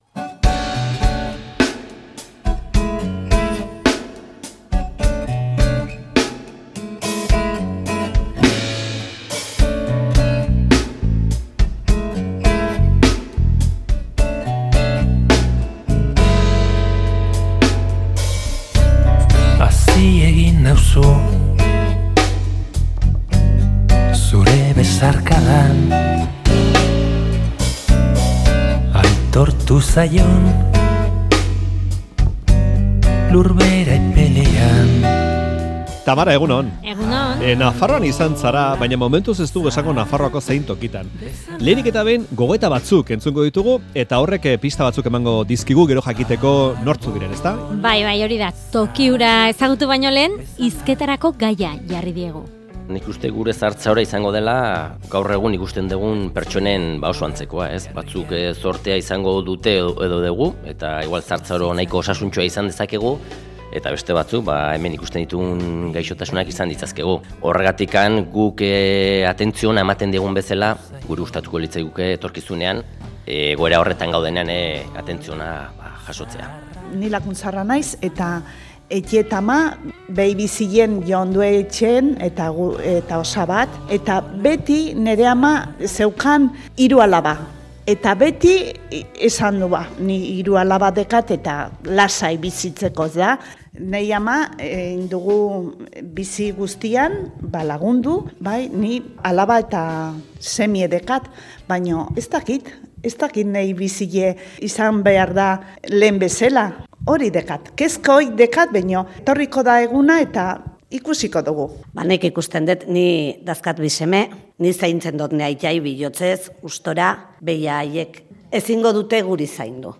Audioa: 25 urteko eskarmentua duen Josefina Arregi psikogeriatrikoan izan da 'Faktoria'. Bertako langile, gaixo eta euren senideekin hitz egin dugu.